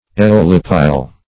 Eolipile \E*ol"i*pile\, n. [Cf. F. ['e]olipyle.]